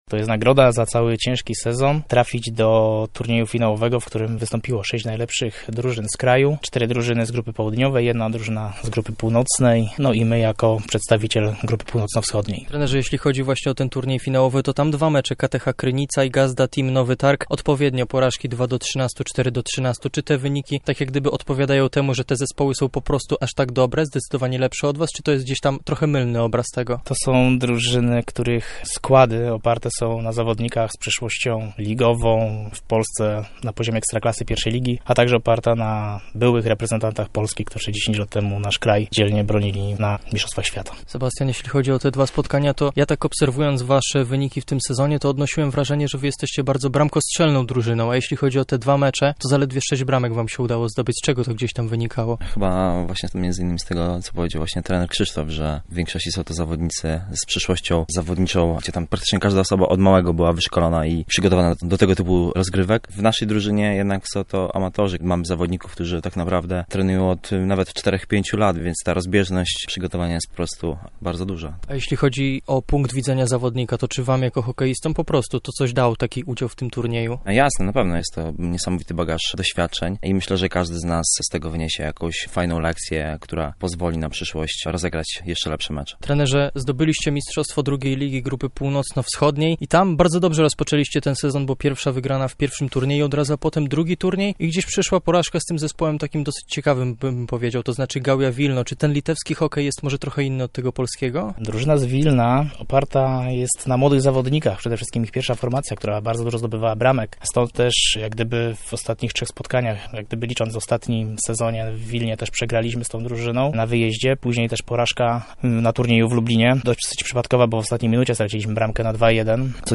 MINIWYWIAD-LHT-Lublin.mp3